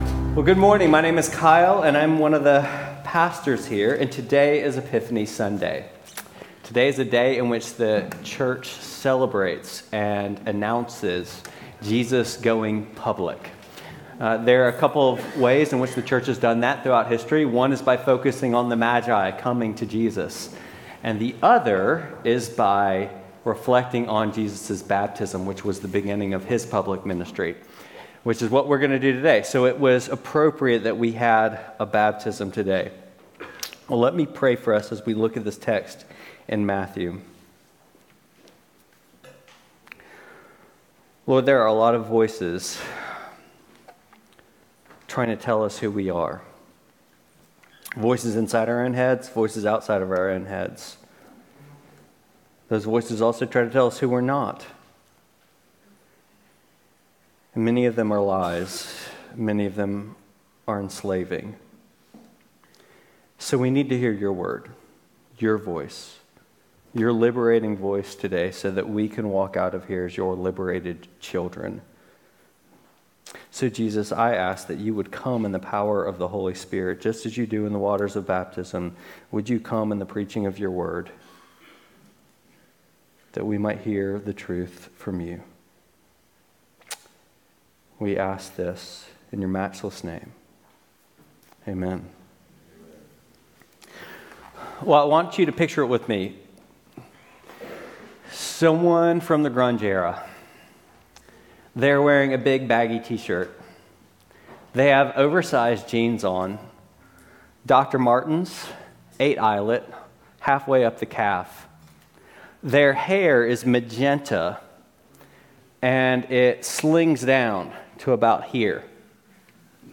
Service Type: Sunday Worship